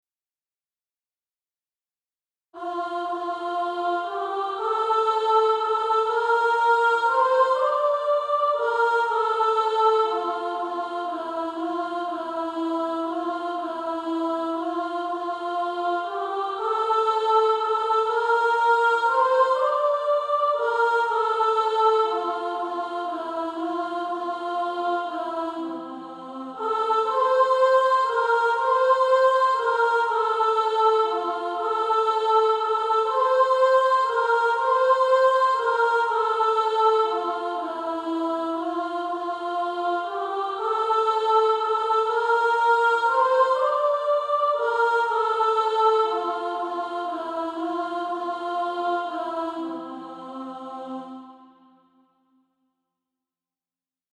Practice then with the Chord quietly in the background.